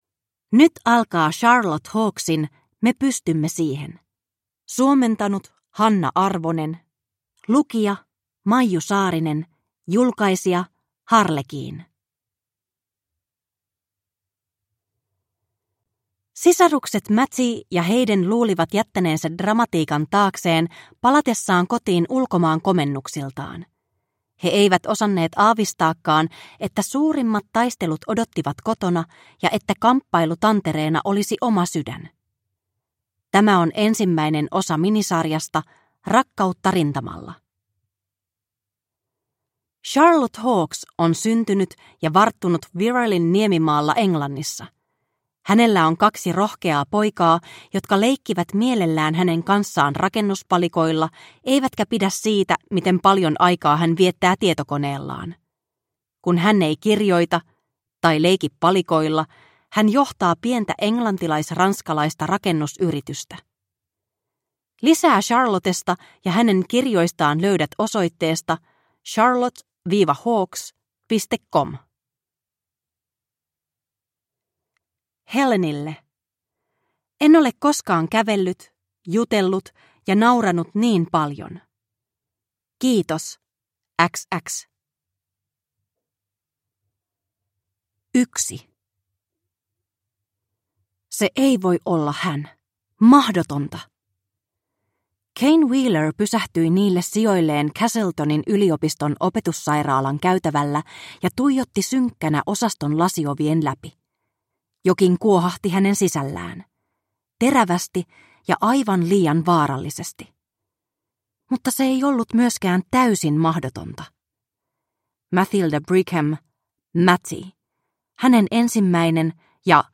Me pystymme siihen (ljudbok) av Charlotte Hawkes